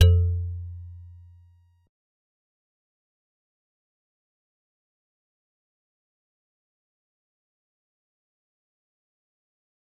G_Musicbox-F2-pp.wav